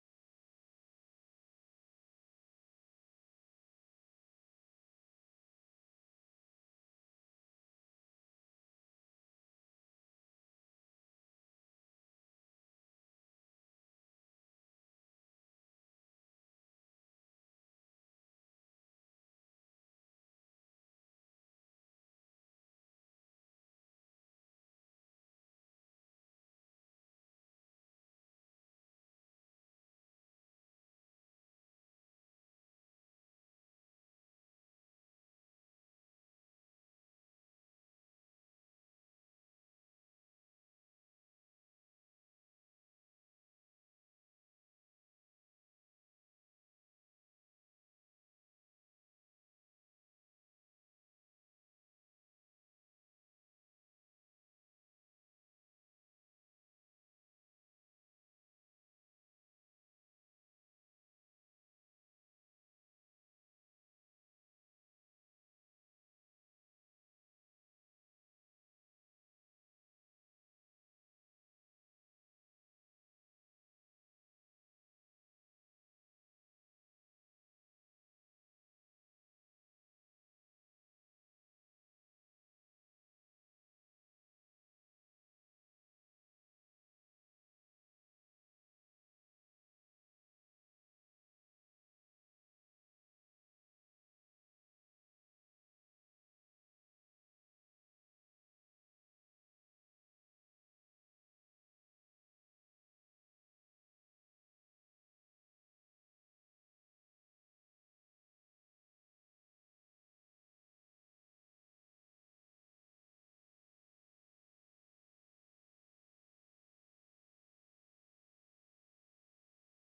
Functie: Presentator
Opgewekte muziek speelt.